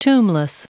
Prononciation du mot tombless en anglais (fichier audio)
Prononciation du mot : tombless